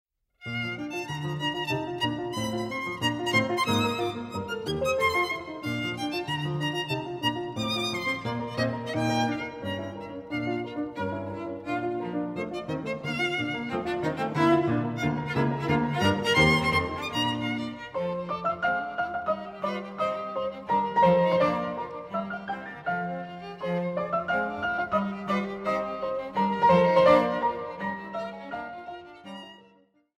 Franz Schubert: Die Klaviertrios
SchubertTrio.mp3